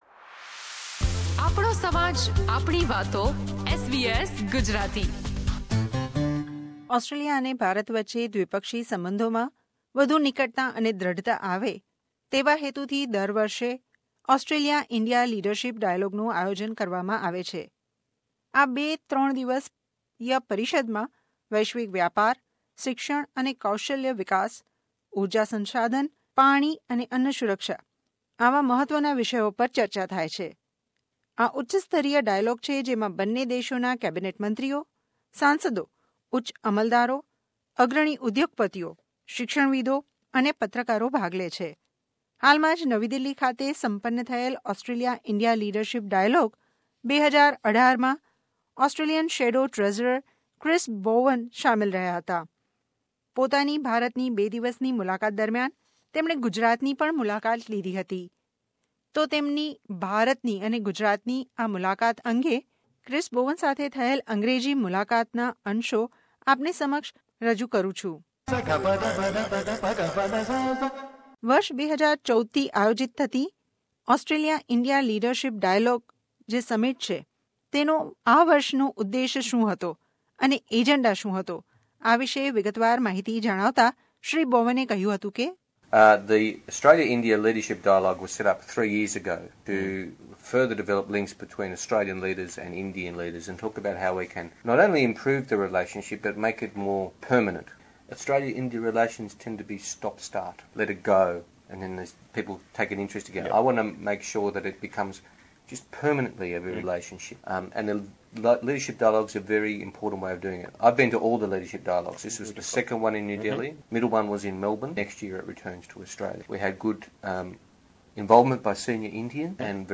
In an exclusive interview with SBS Gujarati, Shadow Treasurer Chris Bowen shared the experience of his first visit to Gujarat and also emphasized on strengthening the sister state relation between NSW and Gujarat